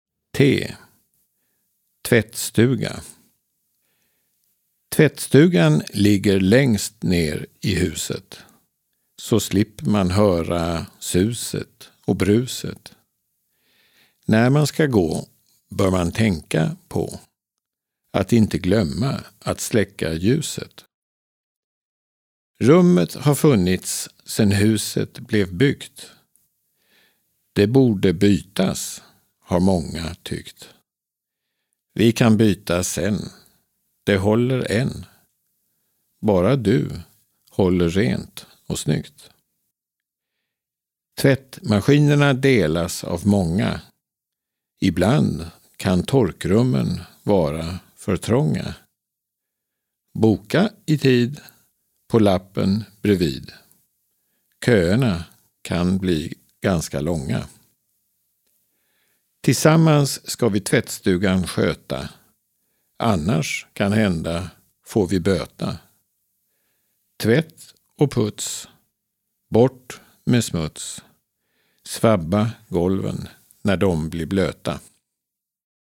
Här på webbplatsen kan man lyssna på ABC-verserna. Lyssna på långsammare inläsningar nedan: